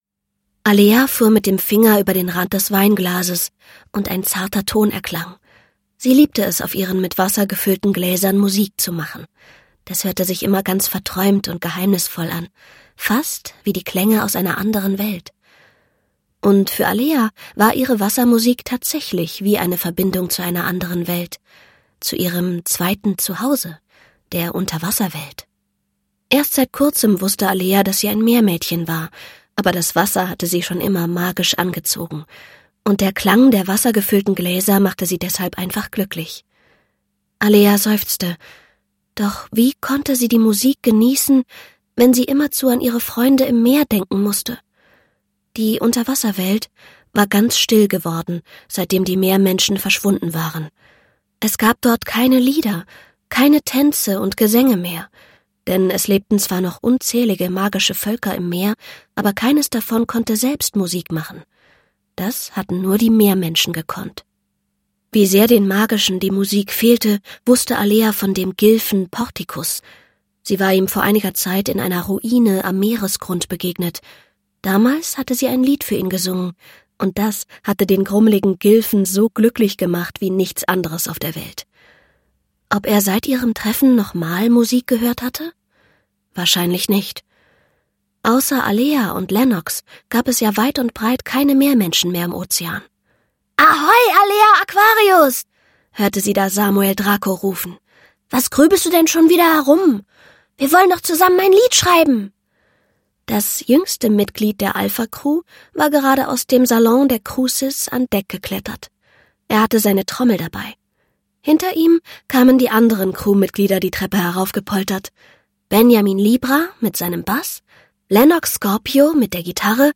Mit Band 2 der Alea-Abenteuer "Ein Lied für die Gilfen" für Kinder ab 5 Jahren erleben auch schon die kleinen Fans den Unterwasserzauber mit der Alpha Cru als ungekürzte Lesung mit Musik besonders atmosphärisch.